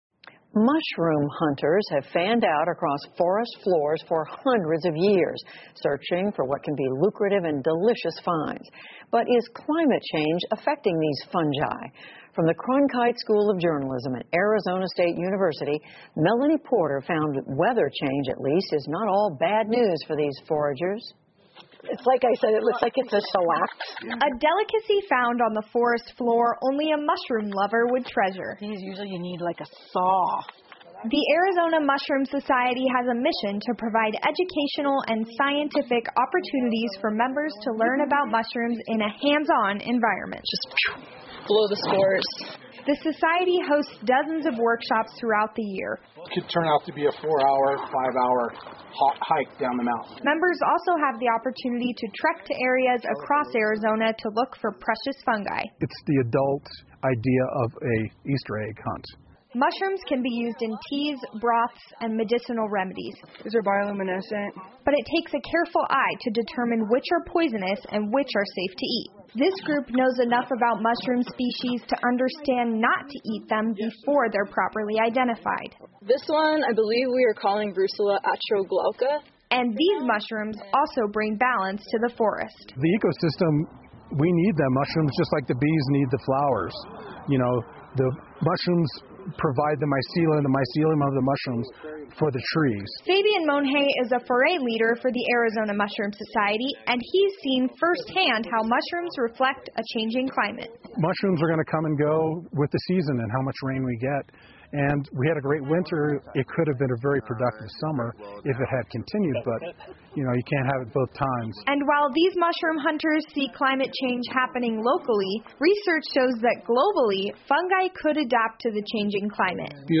美国公共电视网PBS高端访谈节目，其所提供的高质量节目与教育服务，达到媒体告知(inform)、启发(inspire)与愉悦(delight)的社会责任。